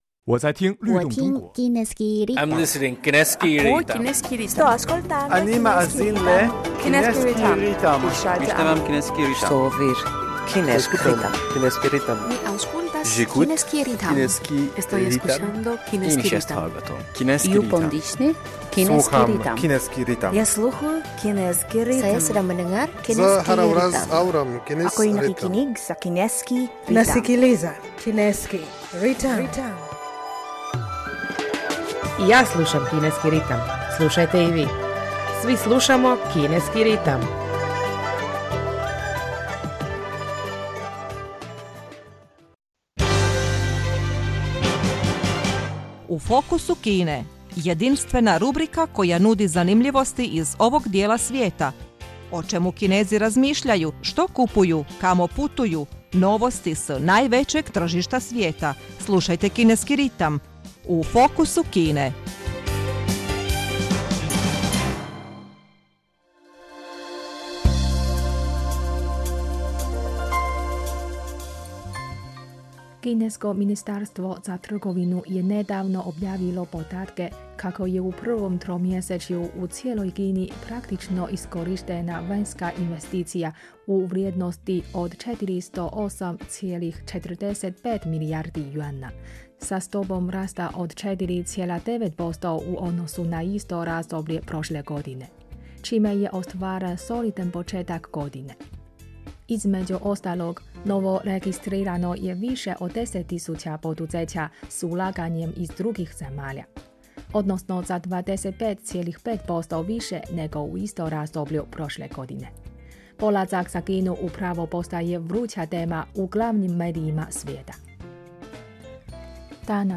Današnja tema je "Kako „Polazak za Kinu!“ postaje konsenzus sve više poduzeća drugih zemalja". Osim toga, poslušajmo kineske pjesme.